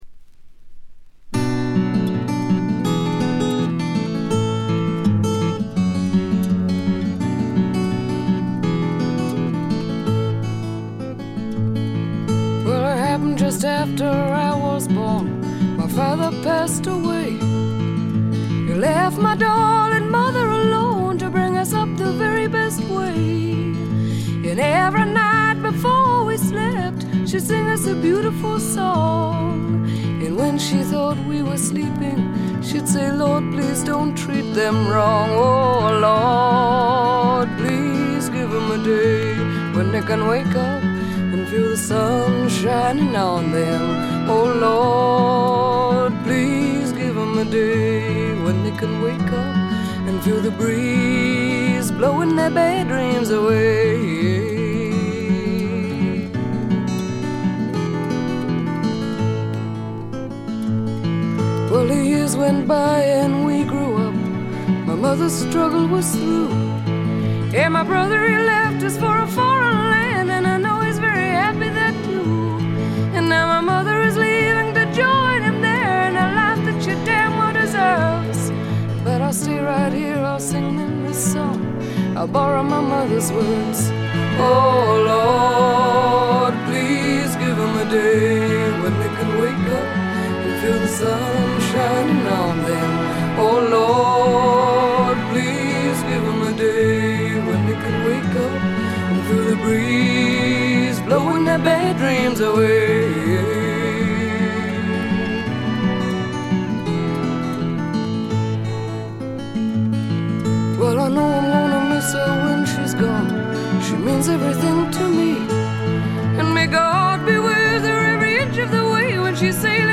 試聴曲は現品からの取り込み音源です。
Recorded at Nova Sound Recording Studios, London